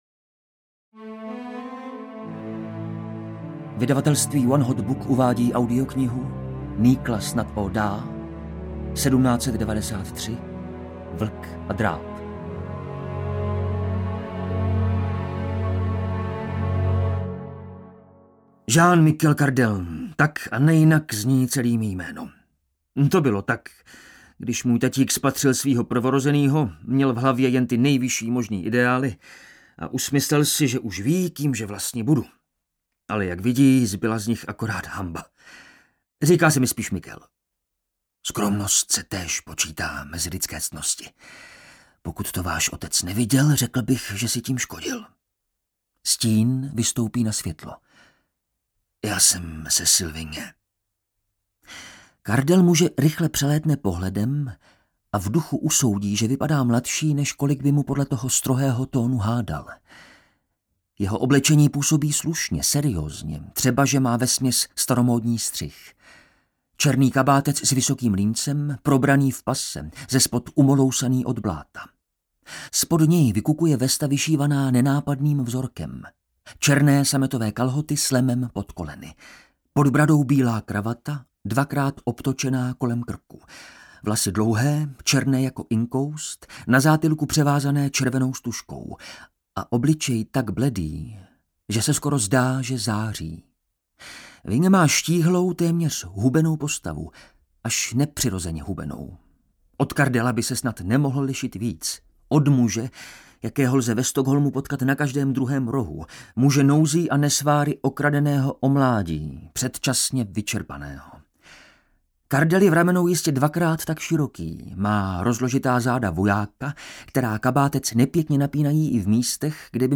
1793 audiokniha
Ukázka z knihy